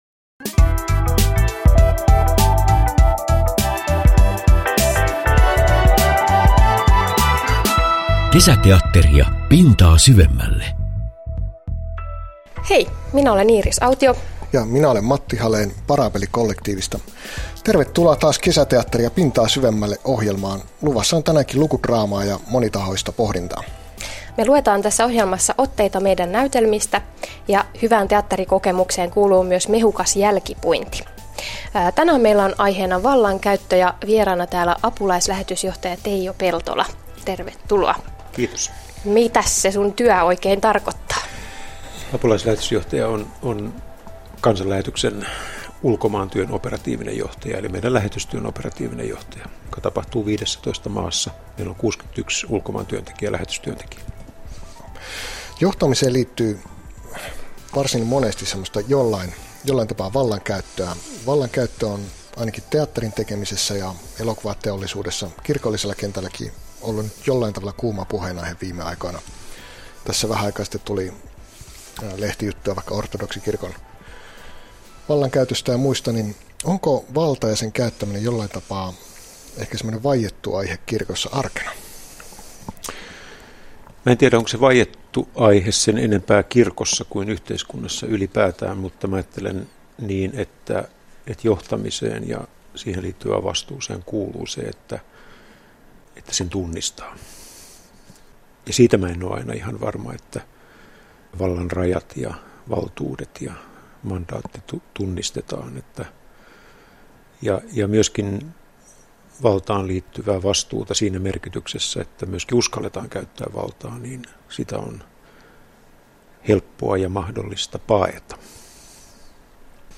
Ihmisyyden ja hengellisyyden teemoja tutkitaan Radio Deissä kesämaanantaisin kello 16.05. Uusinta lähetetään sunnuntaisin samaan aikaan.